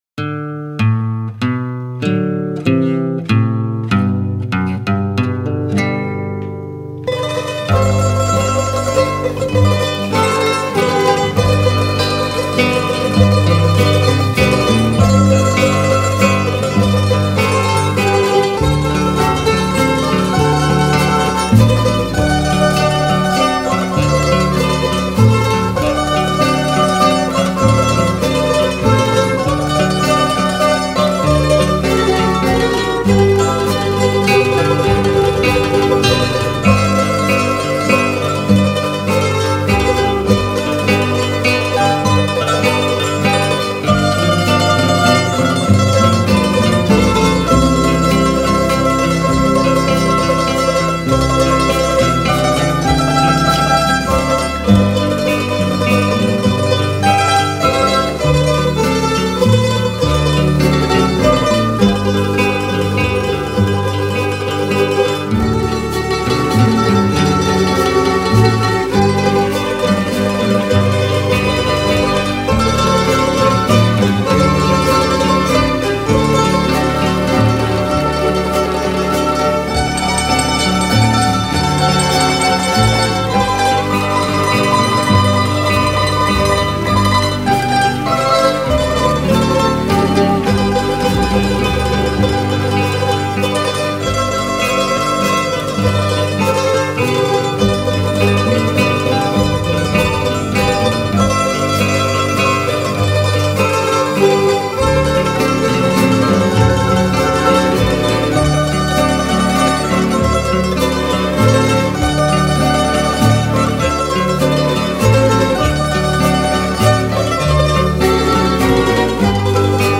03:45:00   Valsa